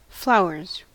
Ääntäminen
Ääntäminen US RP : IPA : /ˈflaʊəz/ US : IPA : /ˈflaʊ.ɚz/ Haettu sana löytyi näillä lähdekielillä: englanti Flowers on sanan flower monikko.